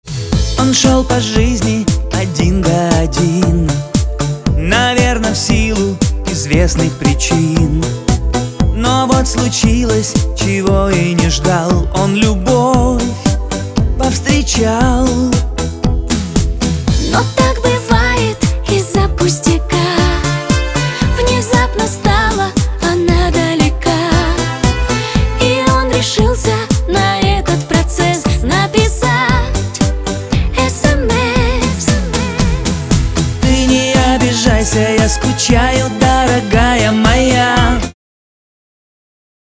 Господа, как сделать чтобы музыка обвалакивала как здесь?
Расчистка в центре не дает такой эффект, что это сайдчейн "многоступенчатый"? или что? все прыгает там где то по краям,...мне нравится как звучит не могу понять как сделать это)))